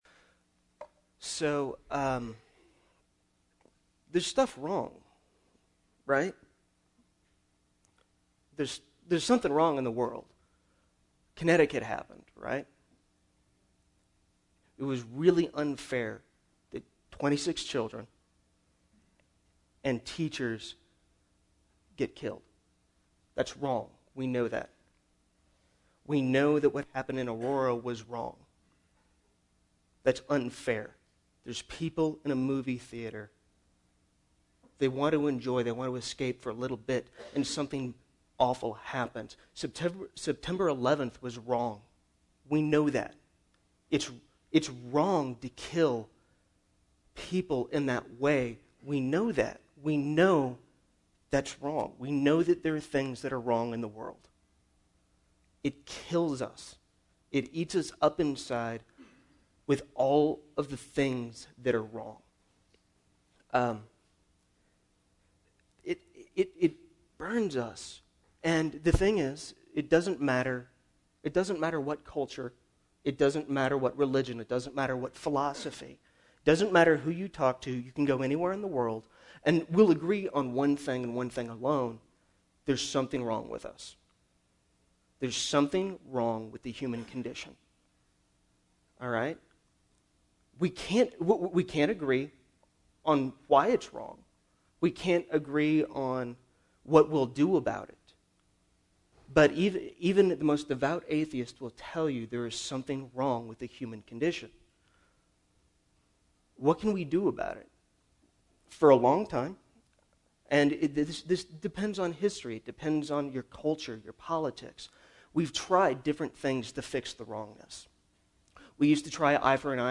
In order to afford him that break, January became, ‘guest speaker’ month; every Sunday either someone from either the church conference or the congregation delivered a sermon. The last Sunday in January was my turn, so I chose Grace.